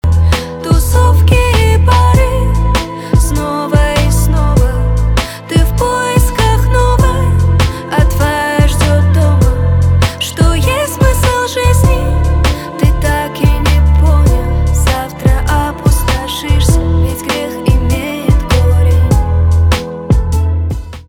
битовые , пианино , чувственные , спокойные